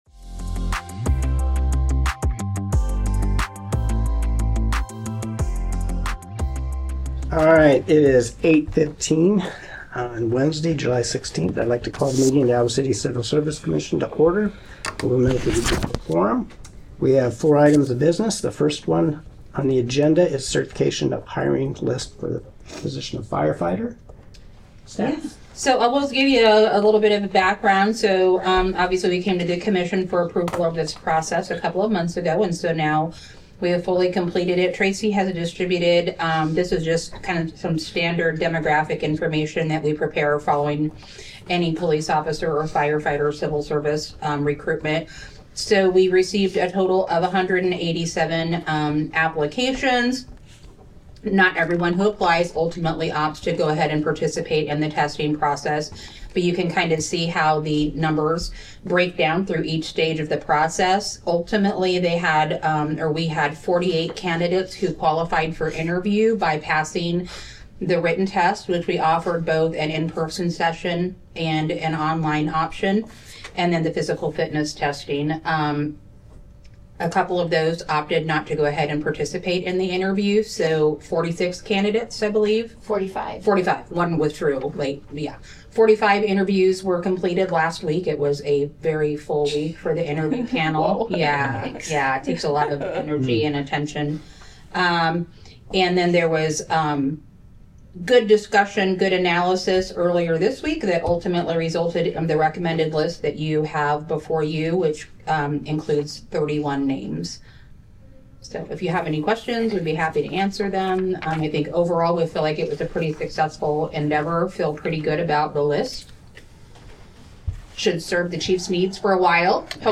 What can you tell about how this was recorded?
A meeting of the City of Iowa City's Civil Service Commission.